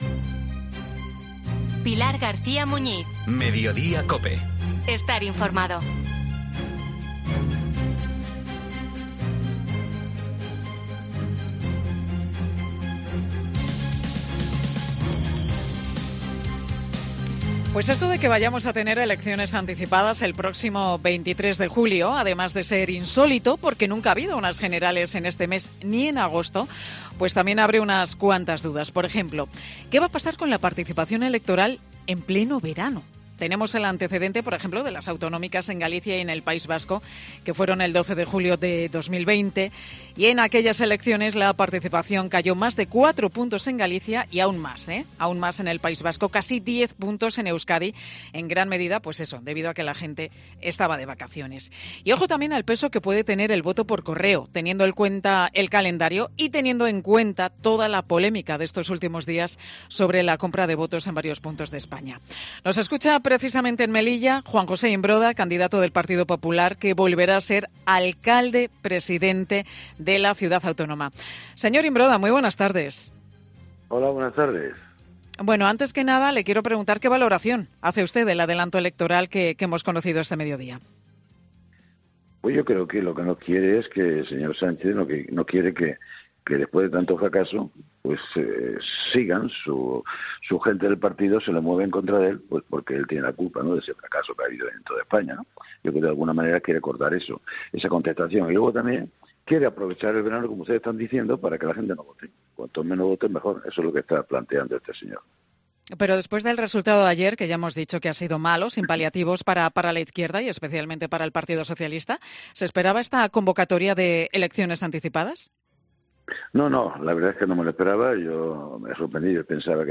Juan José Imbroda, vencedor por mayoría absoluta en la ciudad de Melilla, en Mediodía COPE